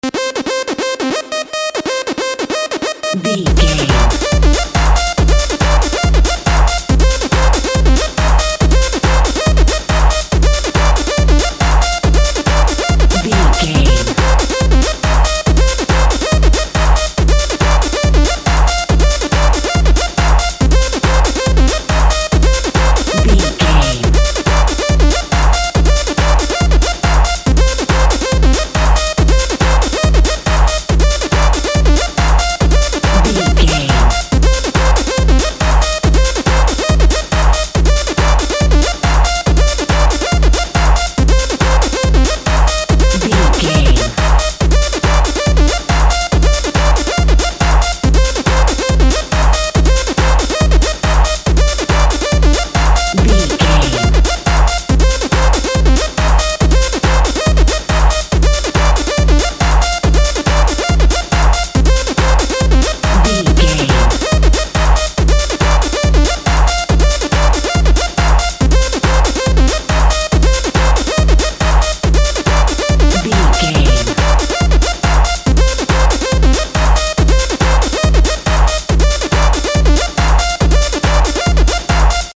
House Techno.
Aeolian/Minor
driving
energetic
futuristic
hypnotic
drum machine
synthesiser
synth lead
synth bass